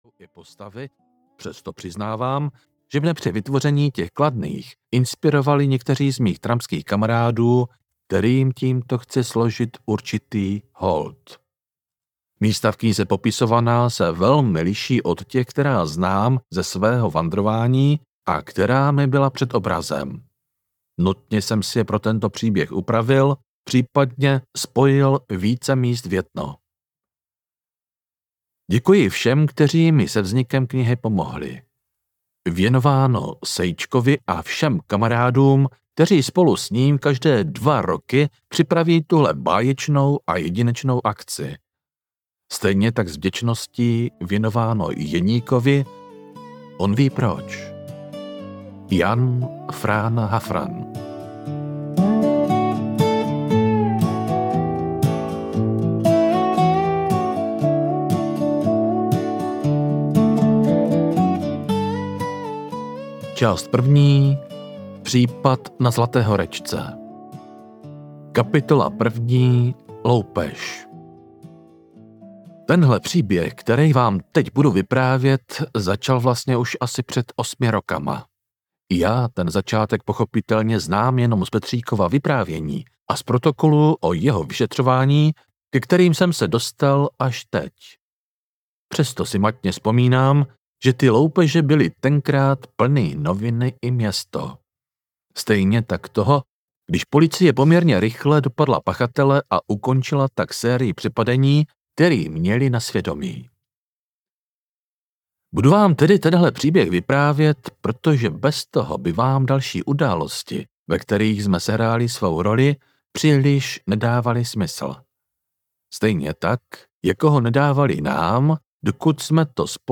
Případ na Zlaté horečce audiokniha
Ukázka z knihy